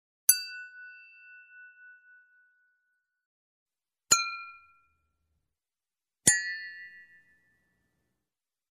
На этой странице собраны звуки хрусталя: легкие перезвоны, тонкие вибрации и магические мелодии.
Звуки ударов о хрусталь